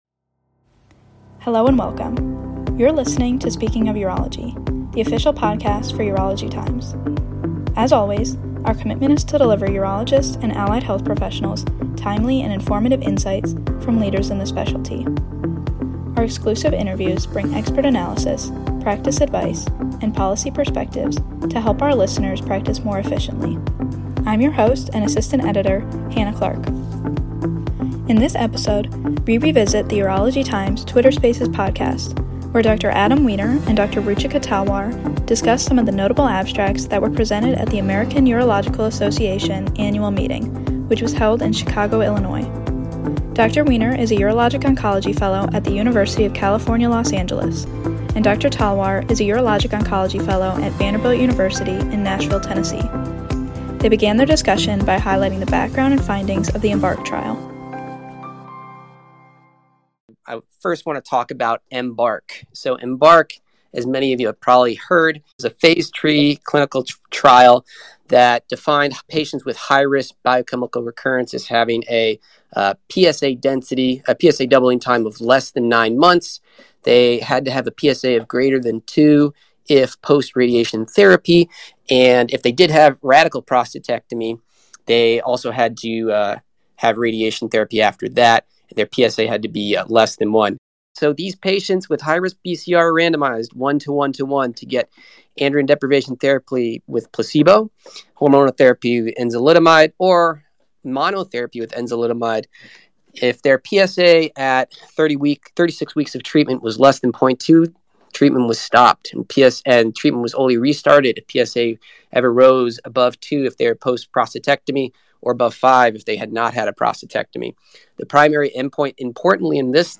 during a live Twitter Spaces event held by Urology Times